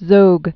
(zōg) Originally Ahmed Bey Zogolli. 1895-1961.